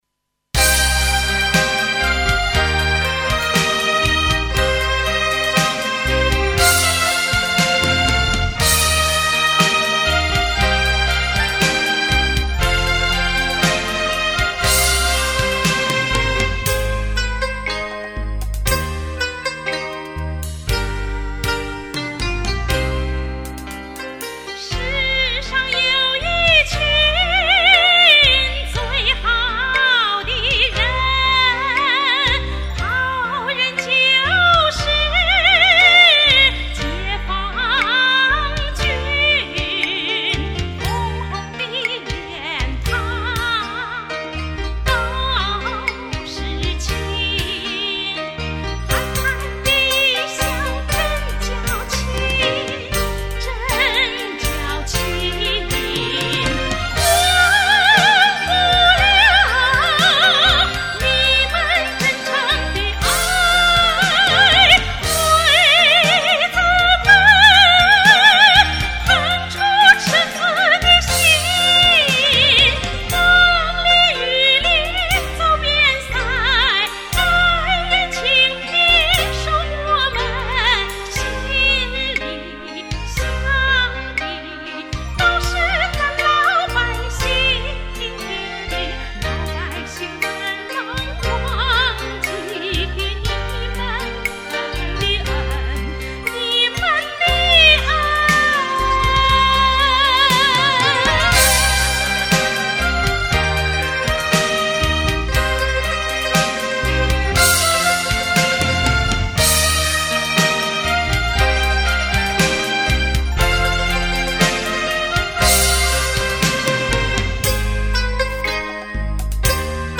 （音樂數碼帶是90年代歷史資料，有點掉碼，敬請原諒！）